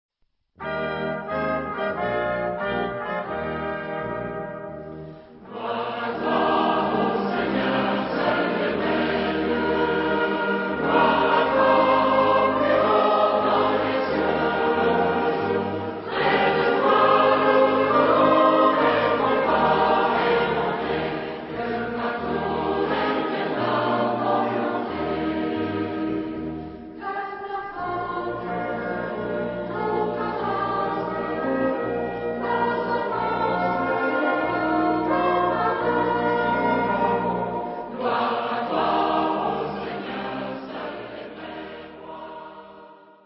Genre-Stil-Form: geistlich ; Gebet
Charakter des Stückes: cantabile ; homophon ; ruhig ; lobend
Chorgattung: SATB  (4 gemischter Chor Stimmen )
Instrumente: Orgel (ad lib)
Tonart(en): Es-Dur